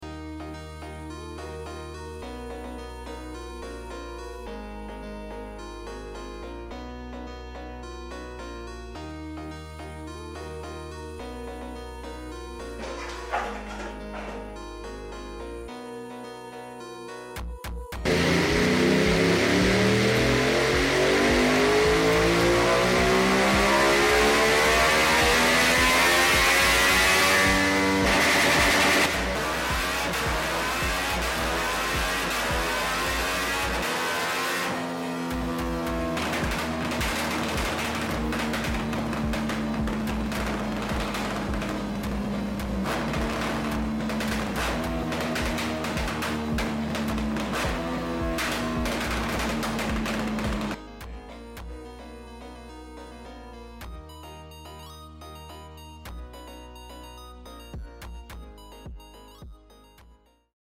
💥Aprilia rs660 35kw 💥 💥Reprogramação sound effects free download
✅Popsandbangs